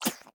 sounds / mob / fox / spit1.ogg
spit1.ogg